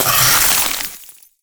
ice_spell_freeze_frost_02.wav